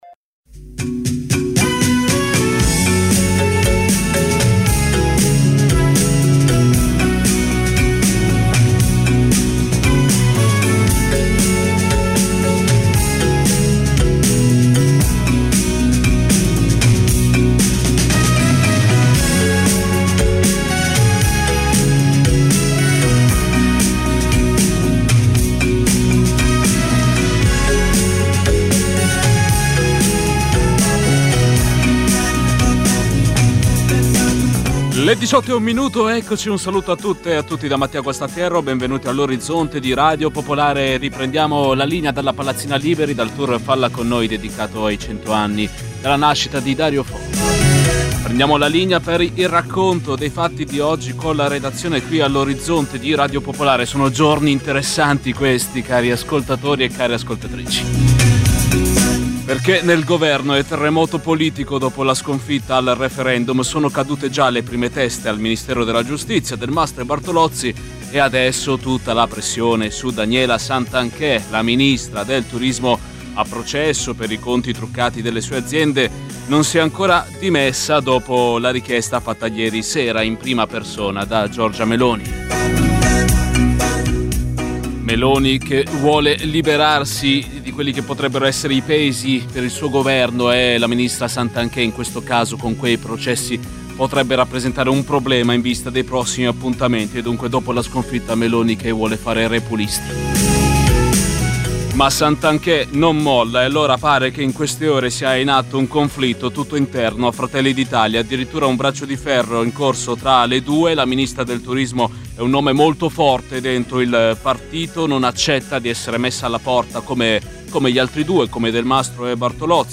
Dalle 18 alle 19 i fatti dall’Italia e dal mondo, mentre accadono. Una cronaca in movimento, tra studio, corrispondenze e territorio. Senza copioni e in presa diretta.